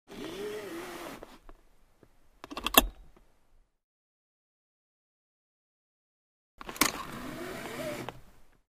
Радио и рингтоны » Звуки » Шум транспорта » Звуки Porsche
Звуки Porsche
На этой странице собраны лучшие звуки Porsche: рев двигателей, работа турбин и другие аудиоэффекты, которые передают дух легендарного бренда.